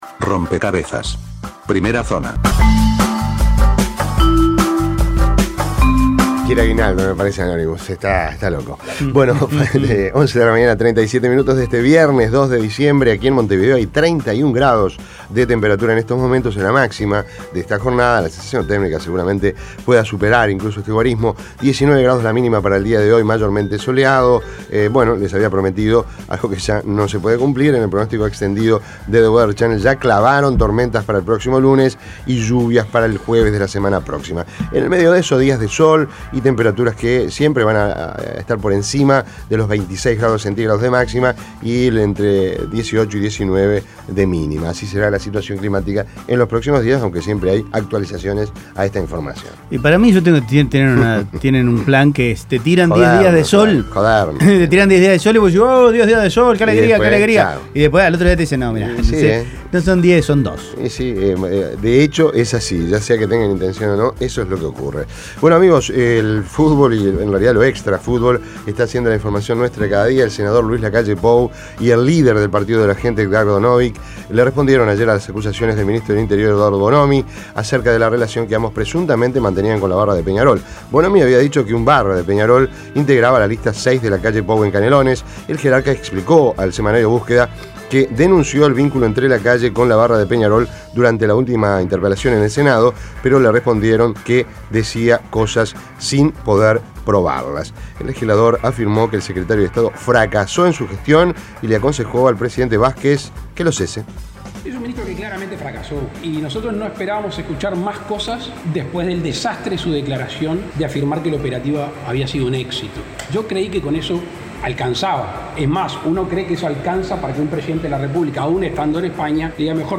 Resumen de noticias Primera Zona Imprimir A- A A+ Las principales noticias del día, resumidas en la Primera Zona de Rompkbzas.